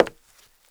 HARDWOOD 1.WAV